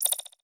Simple Digital Connection 2.wav